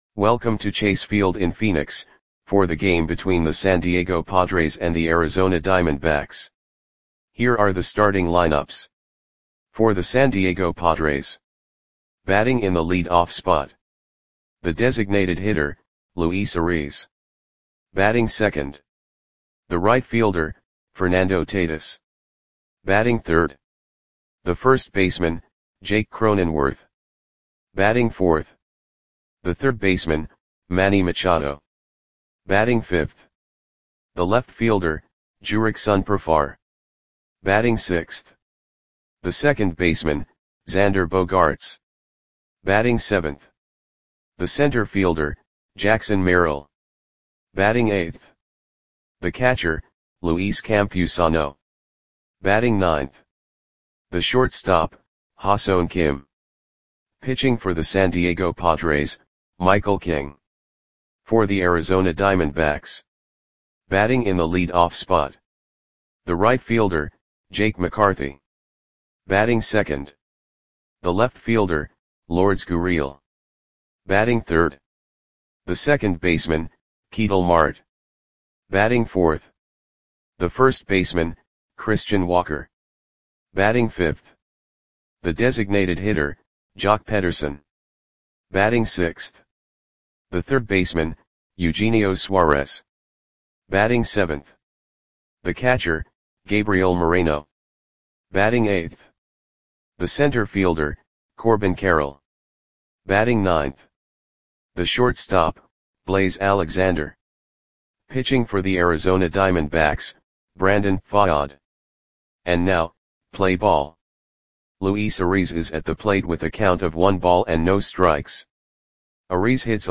Audio Play-by-Play for Arizona Diamondbacks on May 4, 2024
Click the button below to listen to the audio play-by-play.